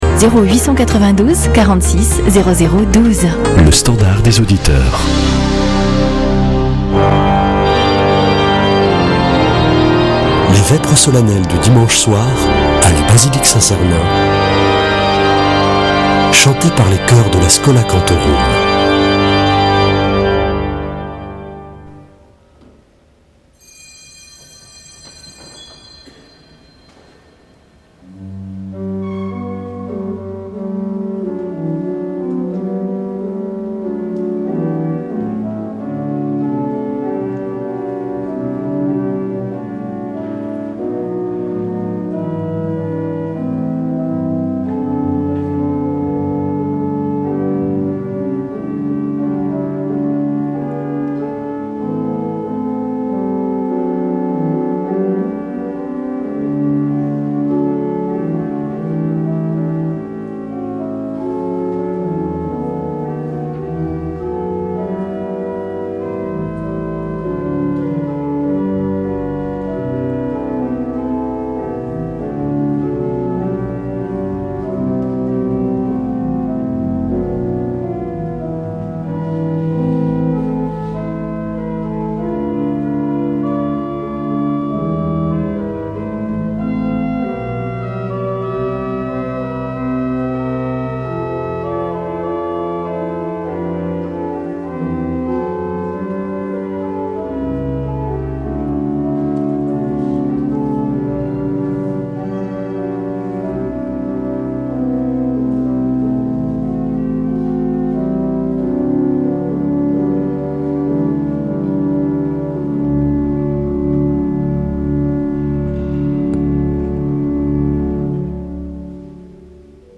Vêpres de Saint Sernin du 22 déc.